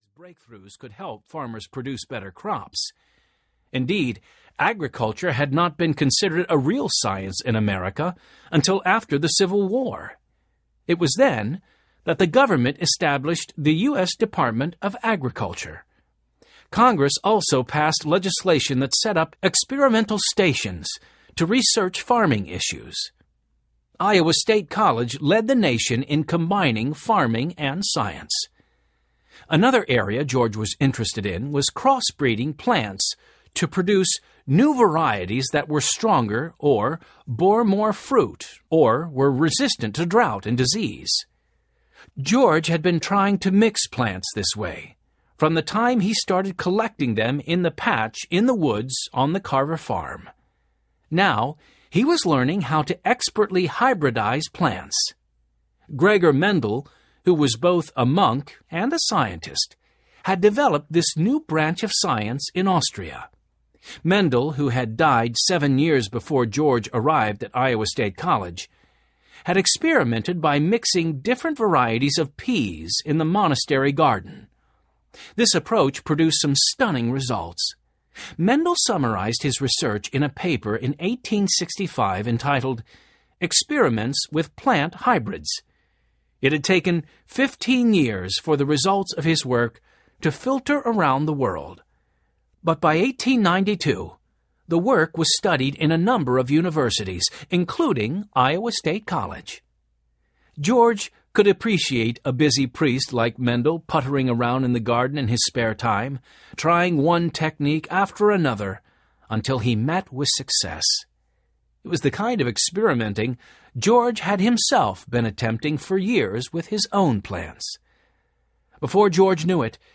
George Washington Carver (Heroes of History) Audiobook
Narrator
4.83 Hrs. – Unabridged